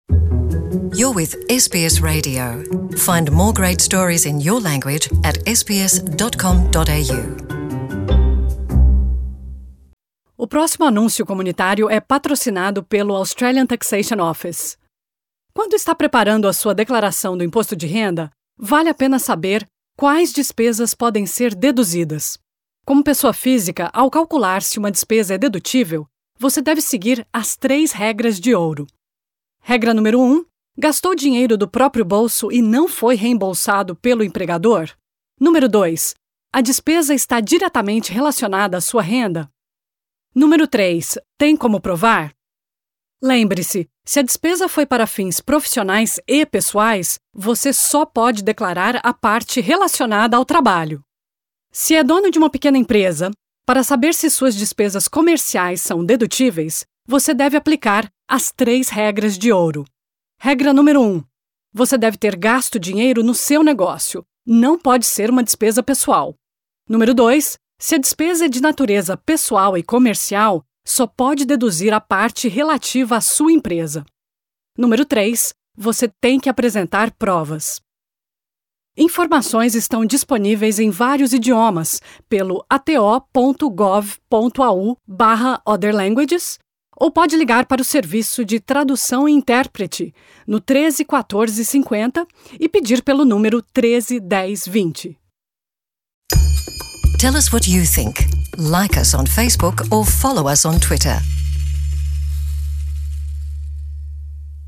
Este anúncio comunitário é patrocinado pelo Australian Taxation Office.